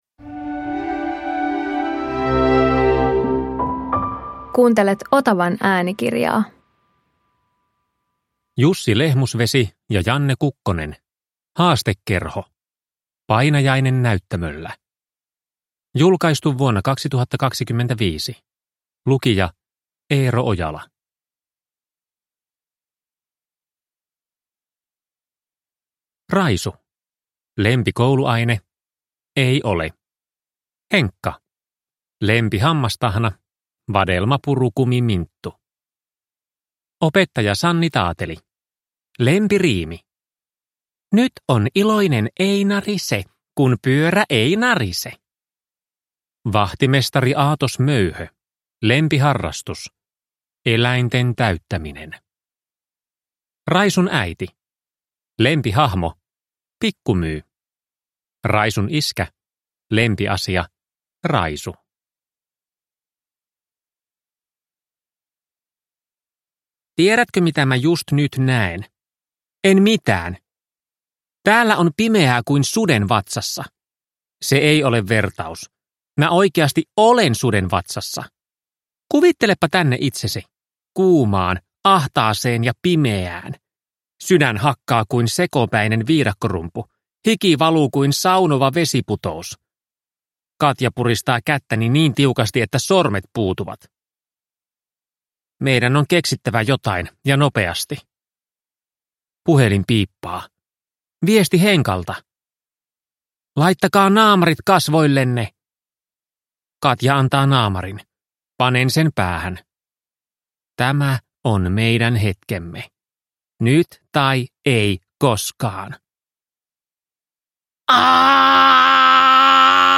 Haastekerho – Painajainen näyttämöllä – Ljudbok